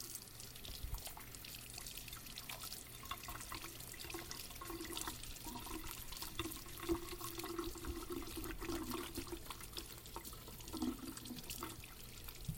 描述：在一个小水槽里以不同的速度和强度流水。 然后把水灌到我的手上，让它像洗脸一样溅下来。
标签： 瓷器 运行 水槽 飞溅 洗涤 湿
声道立体声